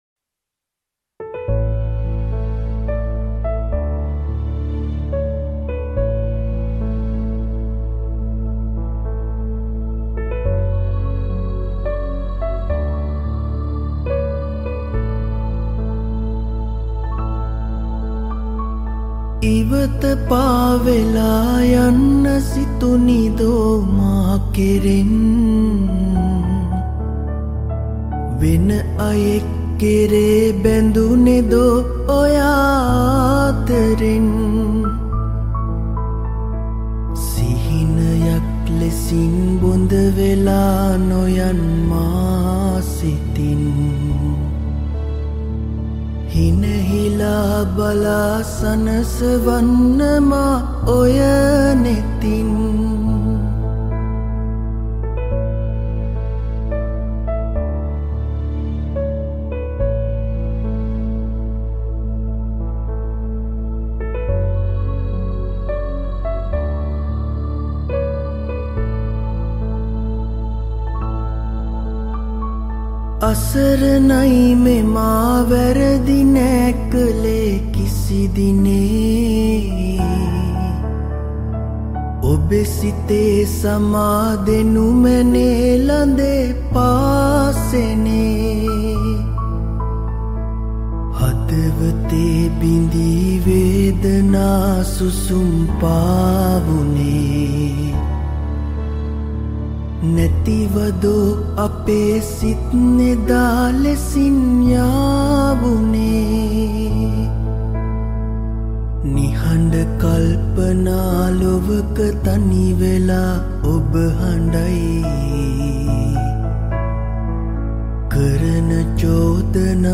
Cover Vocals